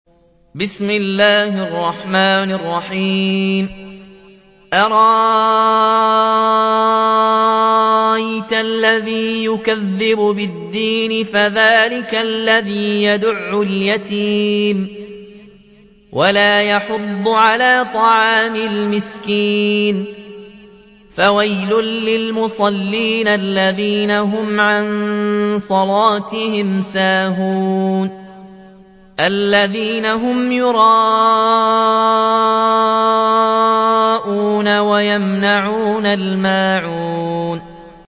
تحميل : 107. سورة الماعون / القارئ ياسين الجزائري / القرآن الكريم / موقع يا حسين